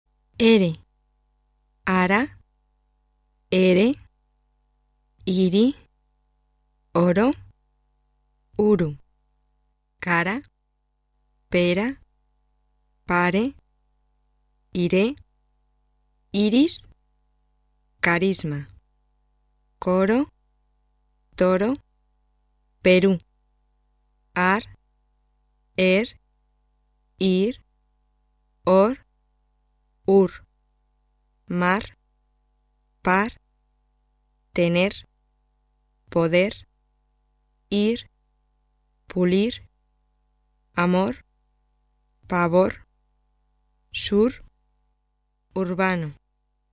R的发音】
R名称是ere，音标【r】。R是单击颤音，发音时，舌尖抬起，与上齿龈接触，让气流通过，使舌尖轻微颤动一到两下，声带振动。当R在词尾时，舌尖只振动一下。